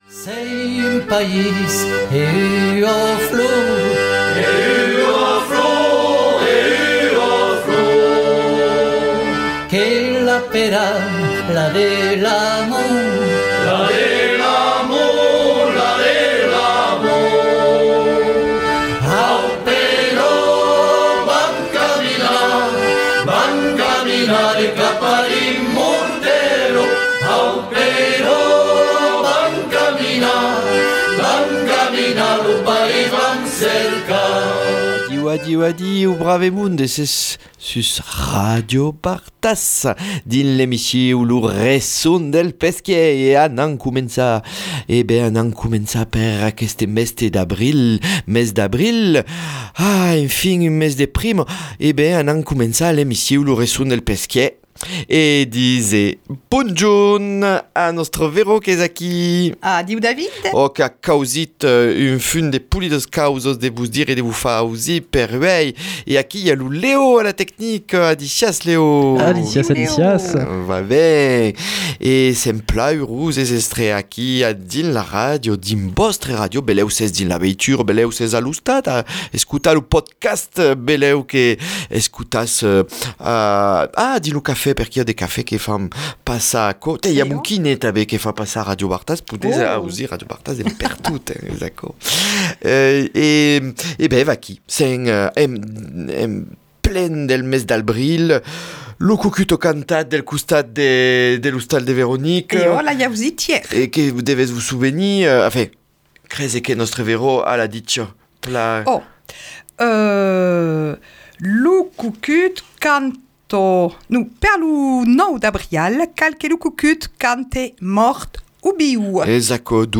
Retrouvez dans cet épisode des proverbe de saison, une lecture, une annonce du festival Total Festum au pont de Montvert et une leçon d’occitan !